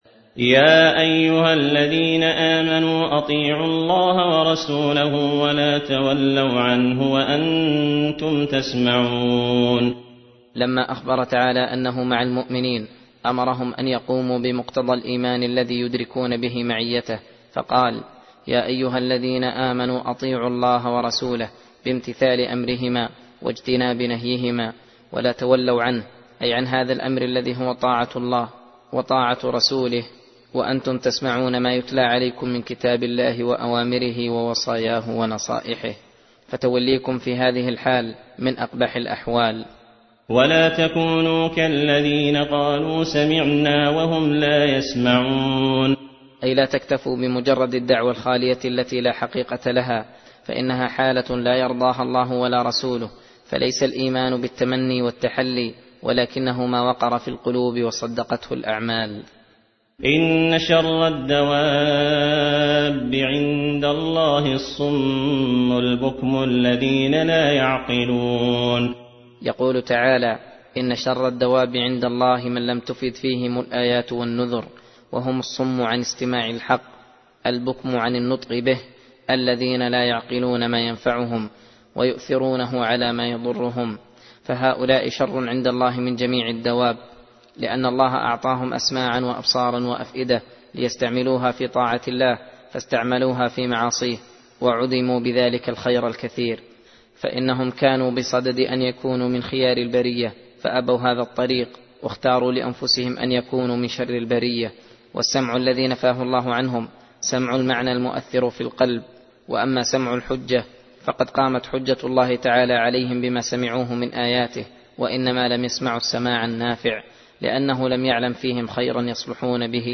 درس (13) : تفسير سورة الأنفال (20-40)